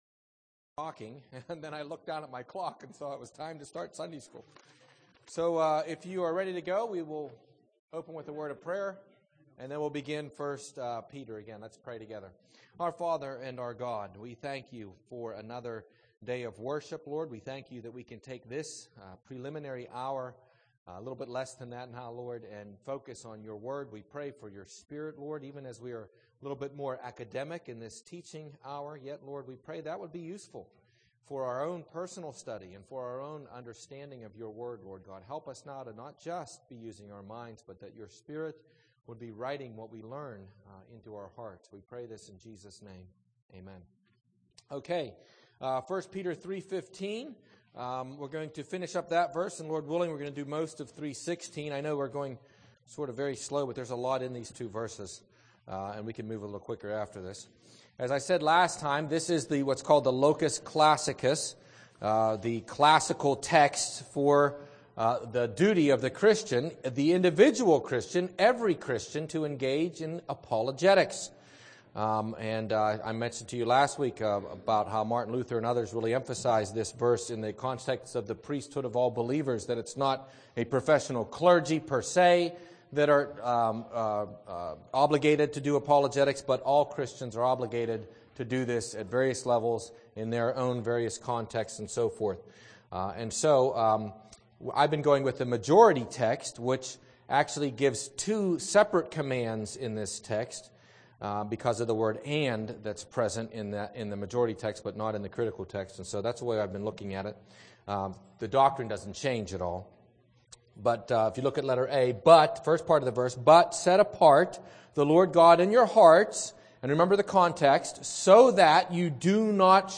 1st Peter Sunday School 11/16